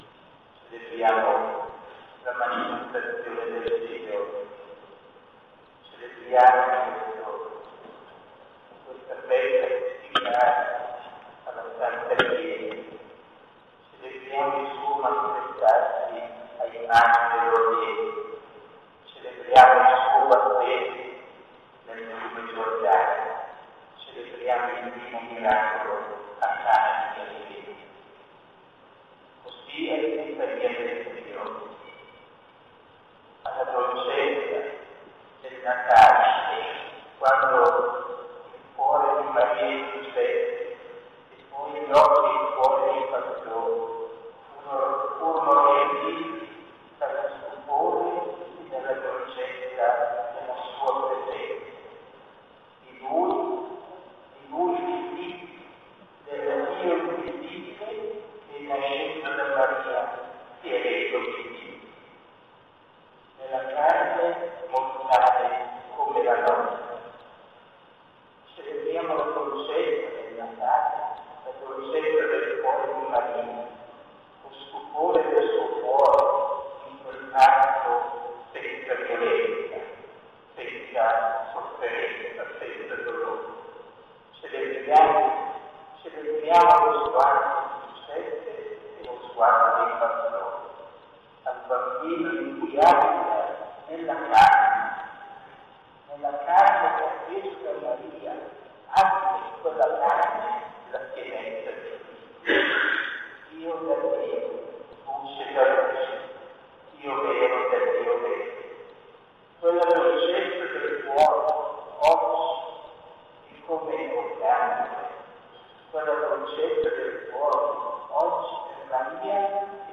OMELIA Epifania del Signore